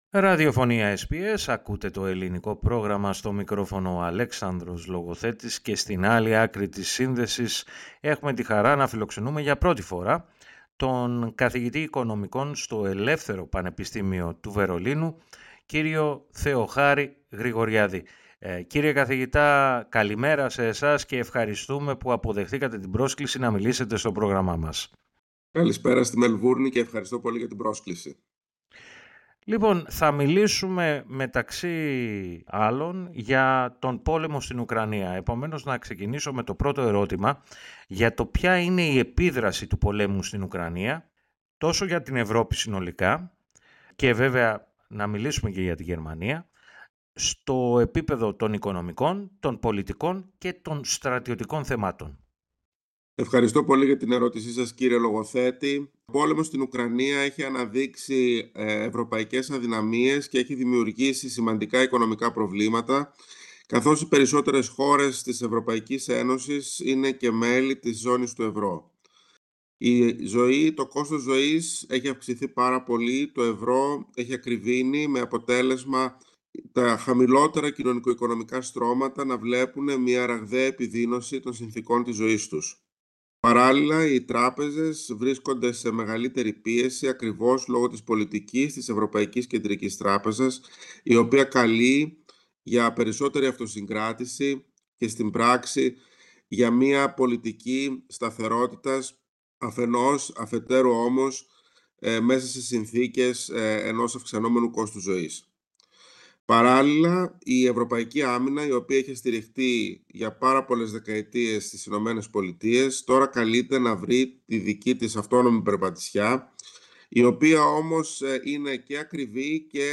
Για απαντήσεις σε αυτά τα ερωτήματα, ακούστε την συνέντευξη, πατώντας PLAY, στην αρχή της σελίδας.